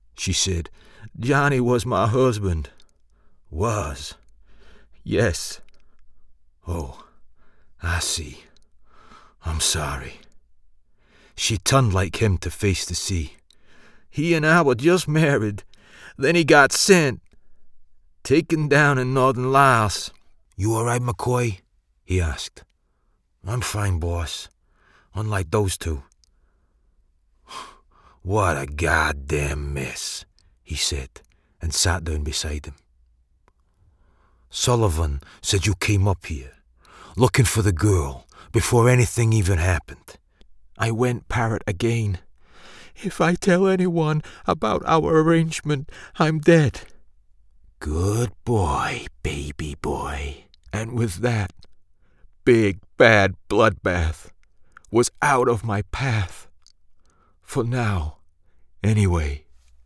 US Reel
Powerful and gritty